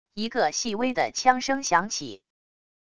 一个细微的枪声响起wav音频